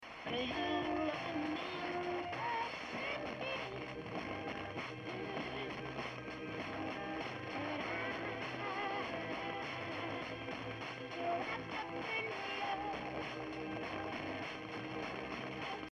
Remember this was a distant weak signal you'd normally never listen to because the sound is a bit fuzzy with all the antennas tested.
Tune Trapper 19-20 (varied from test to test) Fair to Good with a huge variance depending on where I was standing.
antenna_test_tt_reception.mp3